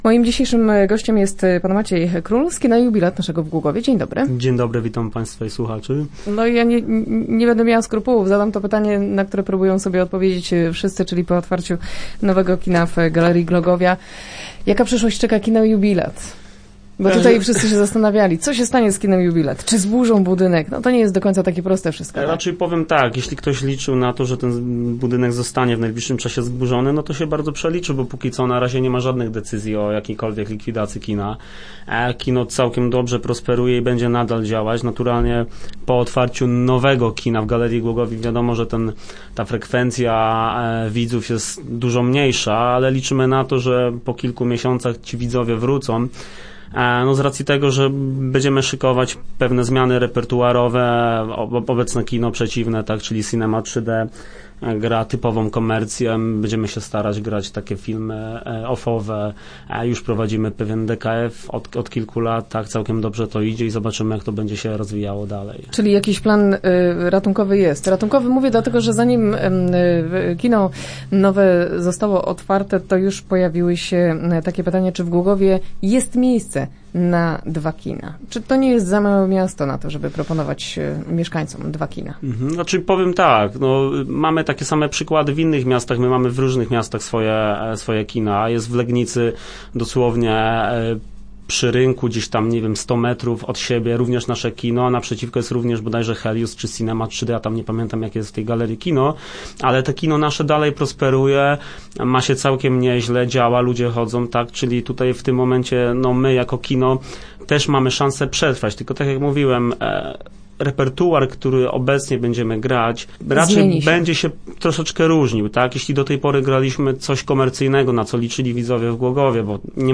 O tym między innymi nasz gość opowiedział w piątkowych Rozmowach Elki.